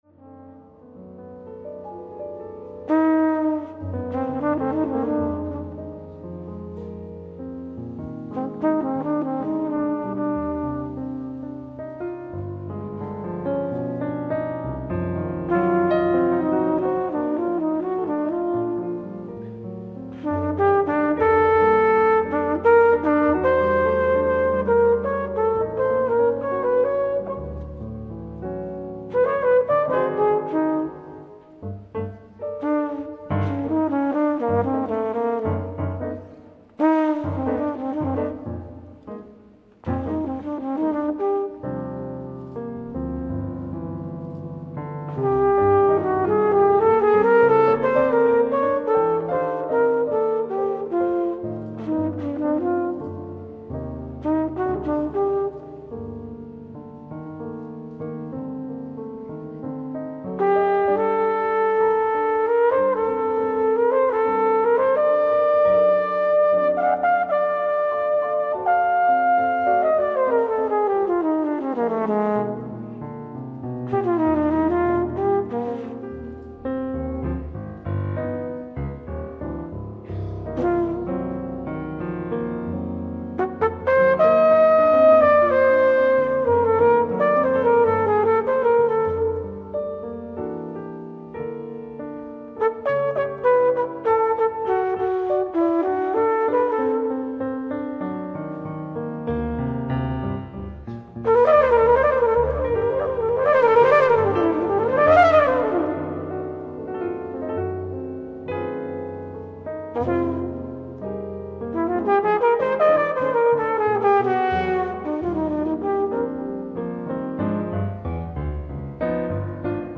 ライブ・アット・アルベローニ美術館、ピアチェンツァ、イタリア 03/25/2023
美音で奏でられるデュオ・ライブ！！
※試聴用に実際より音質を落としています。